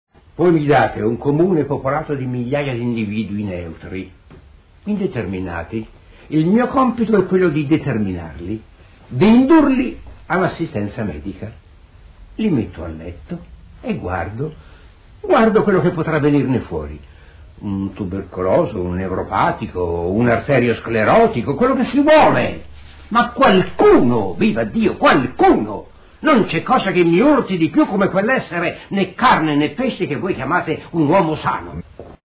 KNOCK: Sergio Tofano